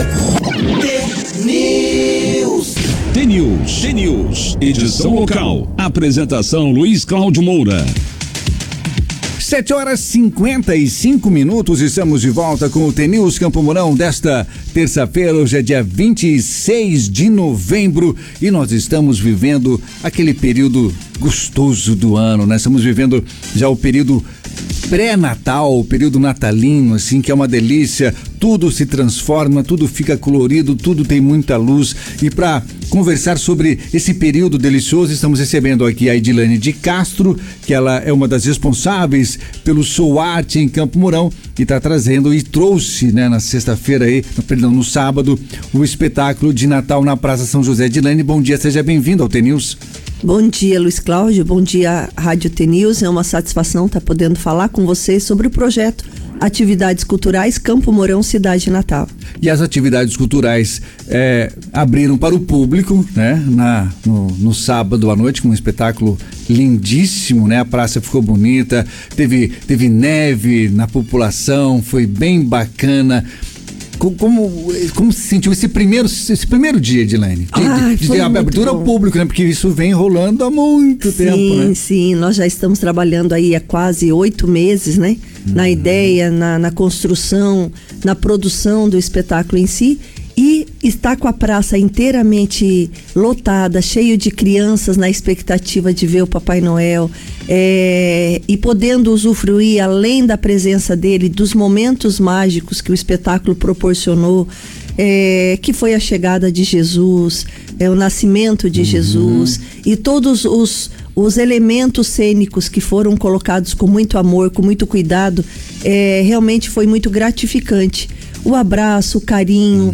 Atividades Culturais do projeto Campo Mourão Cidade Natal 2024, o principal tema da entrevista, com destaque também para a divulgação do espetáculo que será apresentado nesta data na Praça Alvorada do Lar Paraná. Clique no player abaixo para ouvir a íntegra da entrevista.